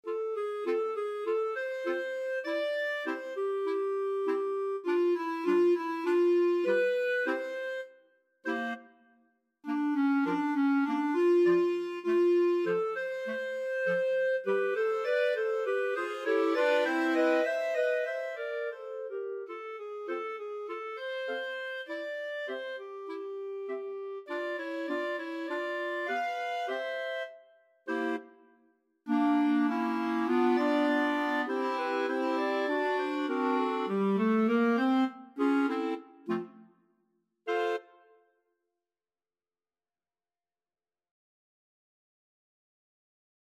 A light-hearted Ragtime-style piece.
4/4 (View more 4/4 Music)
Clarinet Quartet  (View more Easy Clarinet Quartet Music)
Jazz (View more Jazz Clarinet Quartet Music)